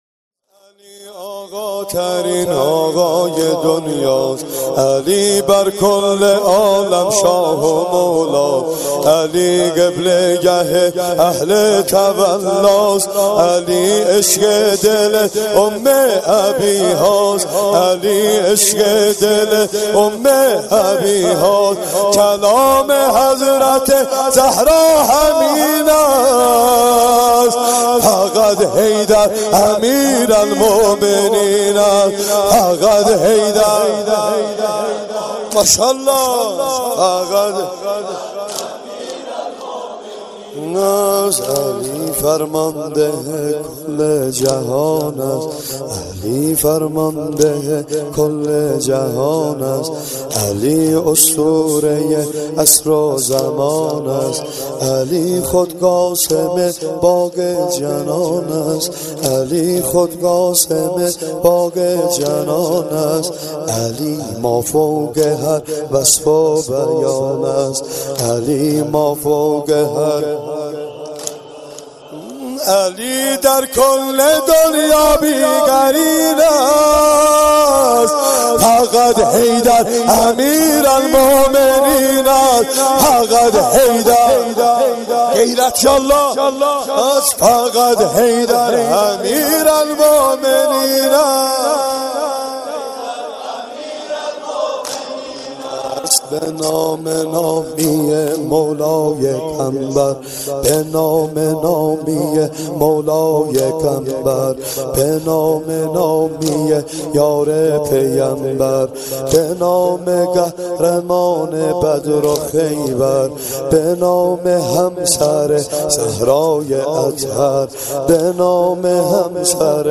خیمه گاه - هیأت الجواد (رهروان امام و شهدا) - واحد علی آقاترین آقای دنیاست